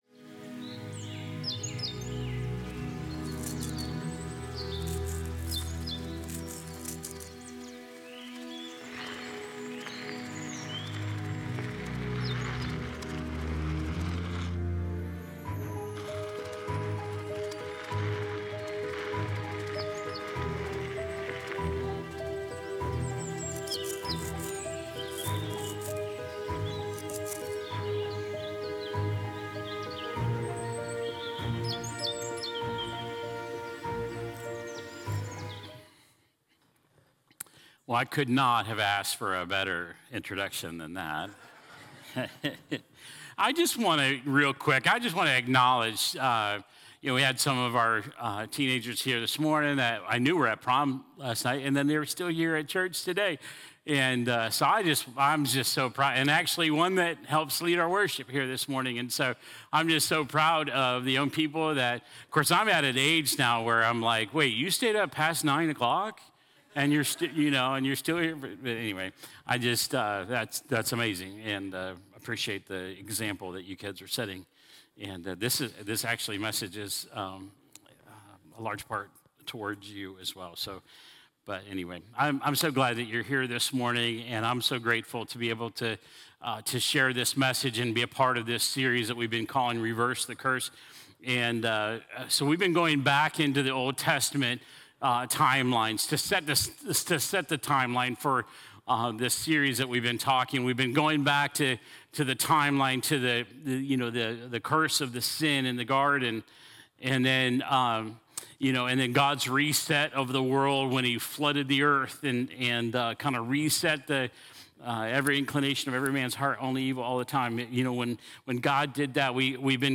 A message from the series "Reverse the Curse."